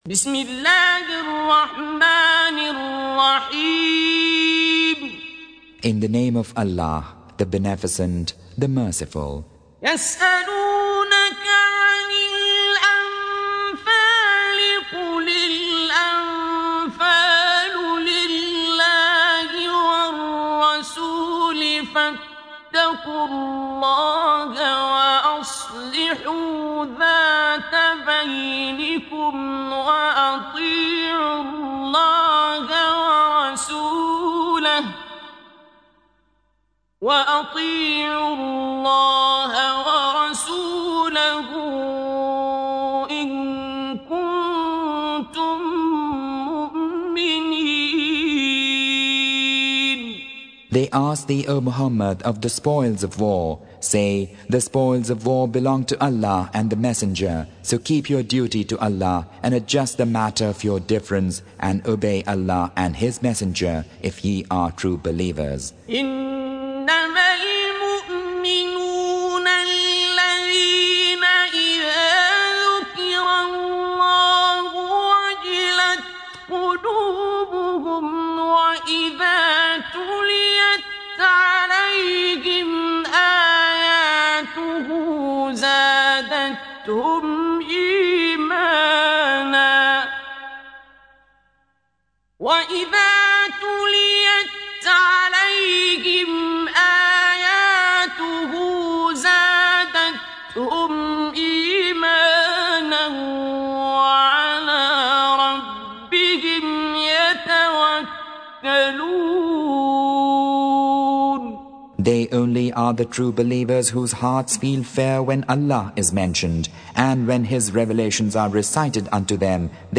Surah Sequence تتابع السورة Download Surah حمّل السورة Reciting Mutarjamah Translation Audio for 8. Surah Al-Anf�l سورة الأنفال N.B *Surah Includes Al-Basmalah Reciters Sequents تتابع التلاوات Reciters Repeats تكرار التلاوات